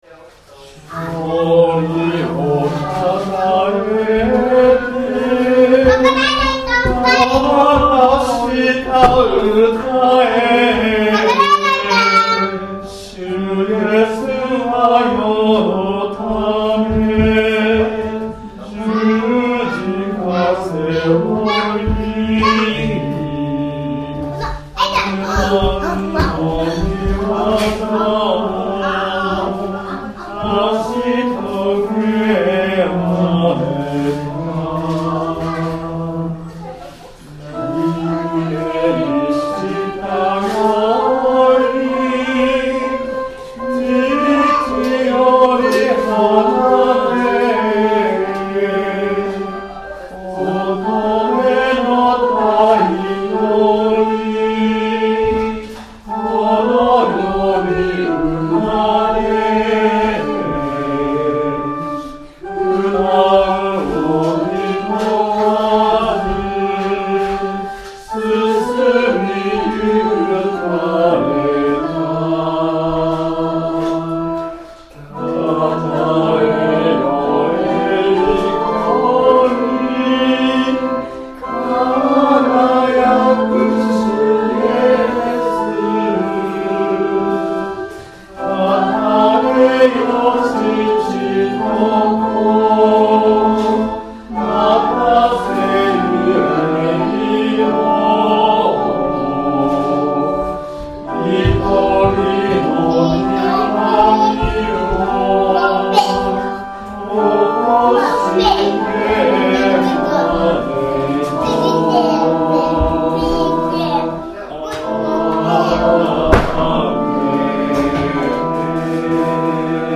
曲：単旋律聖歌 PANGE LINGUA
Temperament = Equal